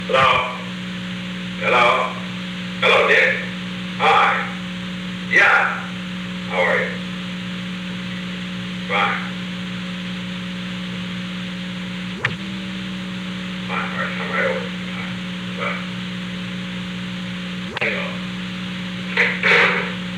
Secret White House Tapes
Conversation No. 430-12
Location: Executive Office Building
The President talked with Richard G. Kleindienst.